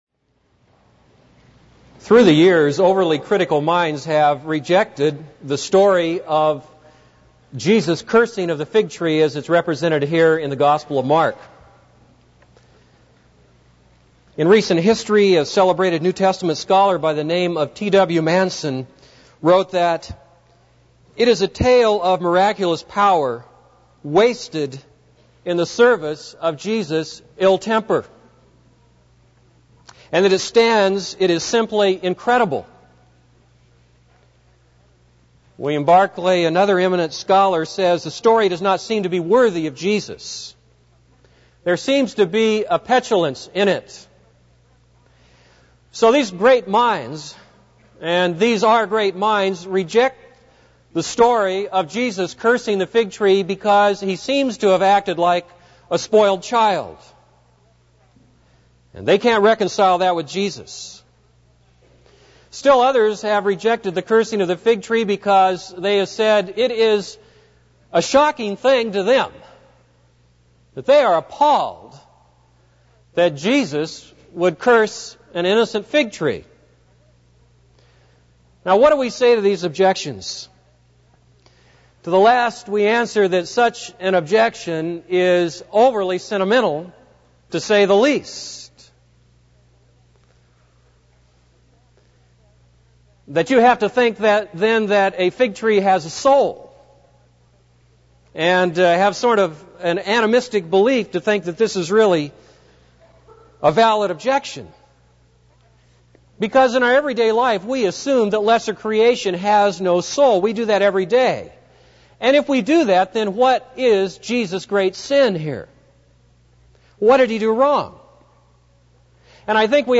This is a sermon on Mark 11:12-19.